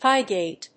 アクセント・音節píg・héaded